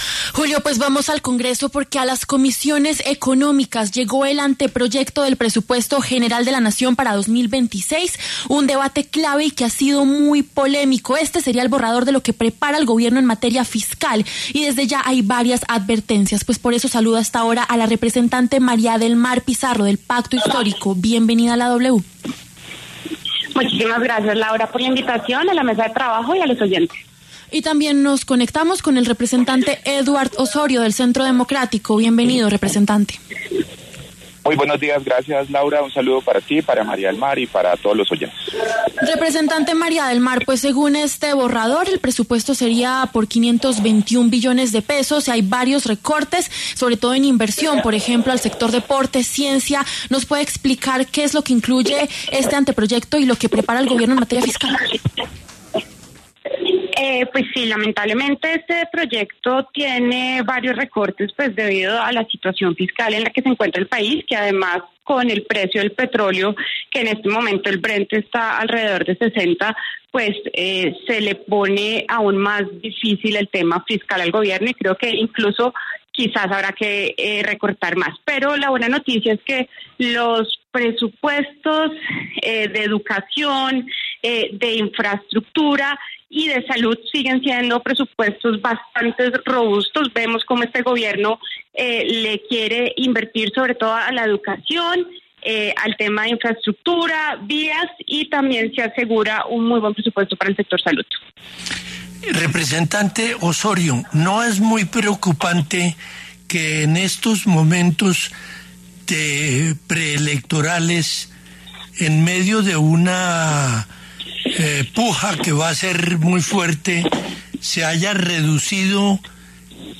Los representantes María del Mar Pizarro, del Pacto Histórico, y Edward Osorio, del Centro Democrático, pasaron por los micrófonos de La W. Preocupa el recorte a la Registraduría, ad portas de las elecciones de 2026.